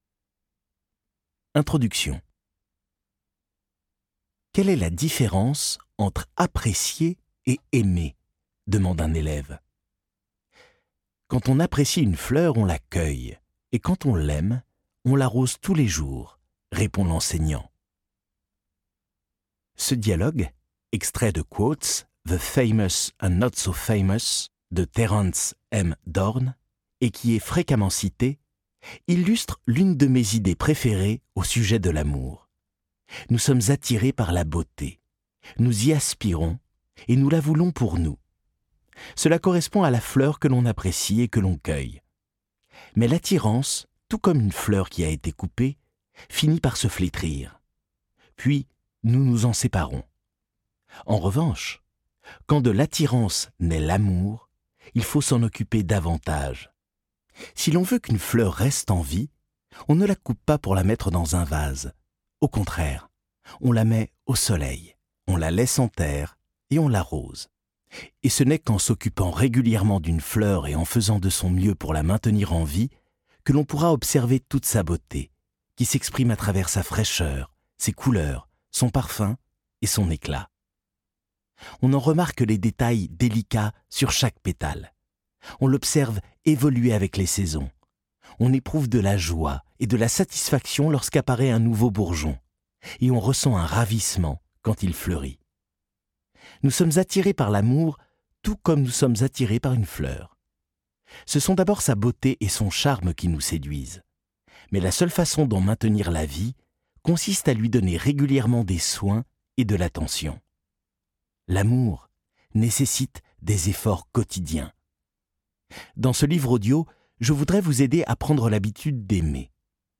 Click for an excerpt - Les 8 lois de l'amour de Jay Shetty